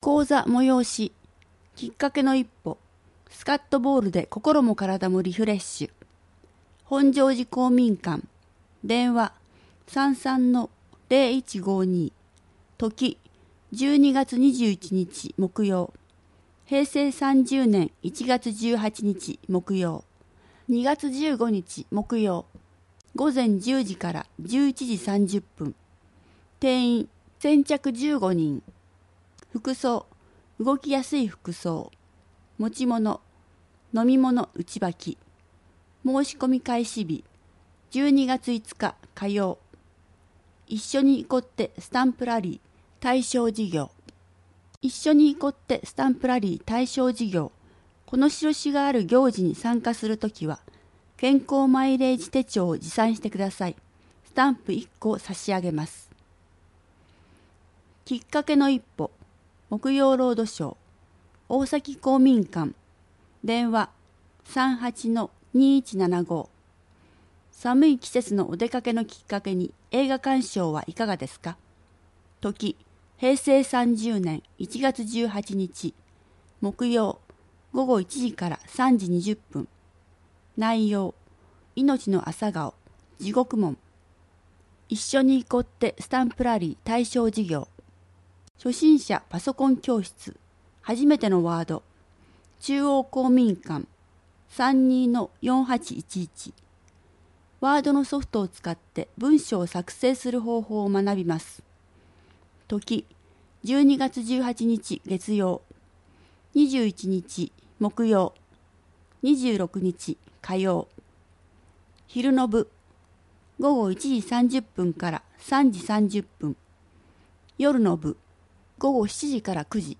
広報さんじょうを音声でお届けします